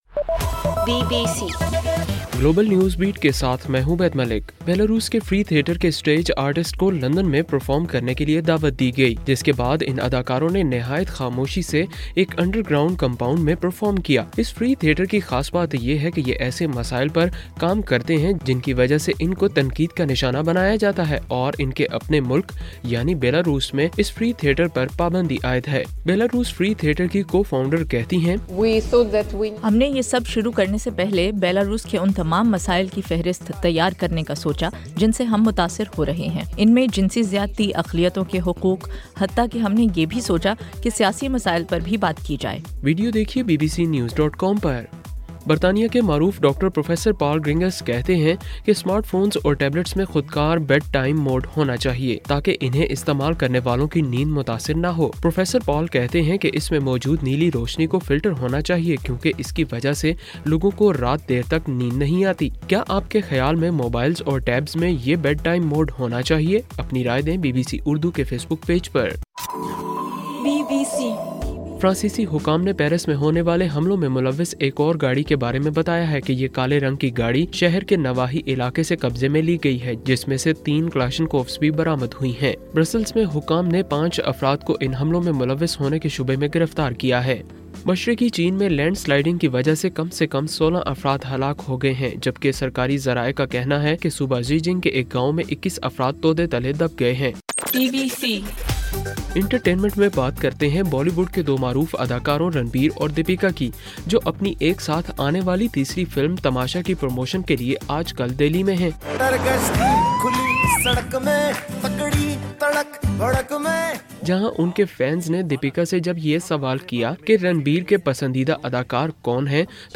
نومبر 15: رات 9 بجے کا گلوبل نیوز بیٹ بُلیٹن